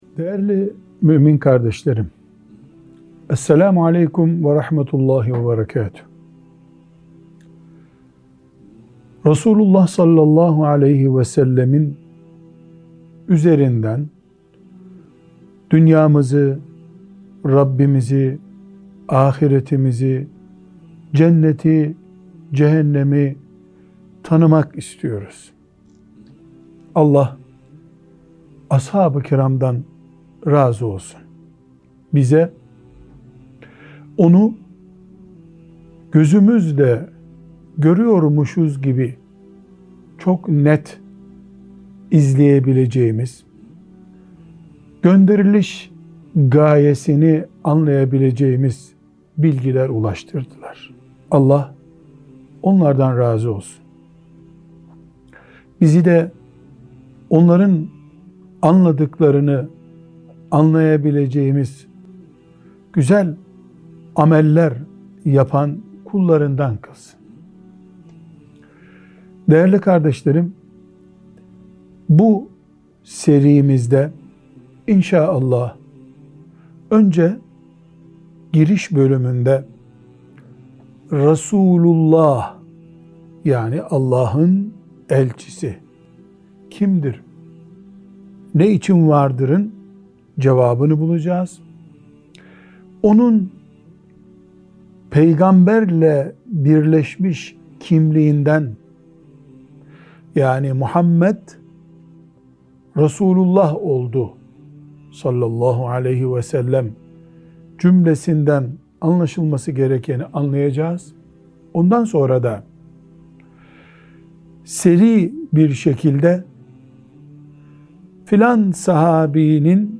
1. Sohbet Arşivi